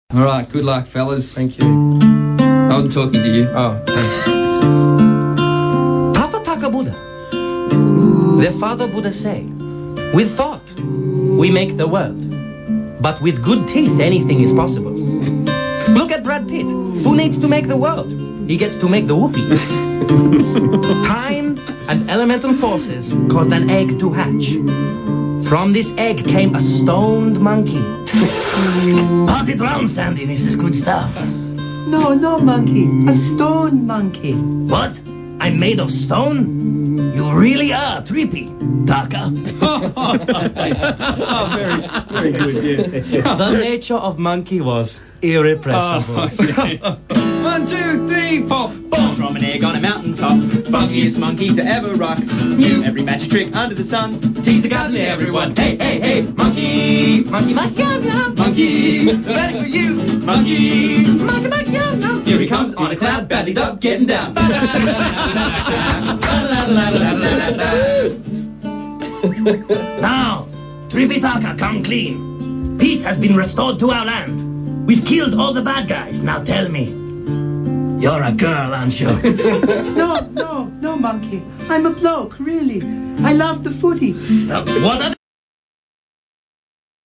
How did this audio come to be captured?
recorded in an unknown radio session